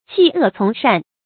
棄惡從善 注音： ㄑㄧˋ ㄜˋ ㄘㄨㄙˊ ㄕㄢˋ 讀音讀法： 意思解釋： 丟棄邪惡行為去做好事。